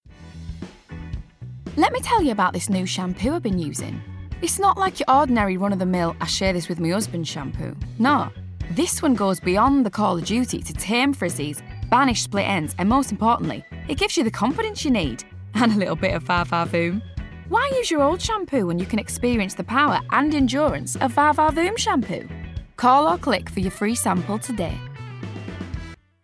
20s-30s. Female. Welsh.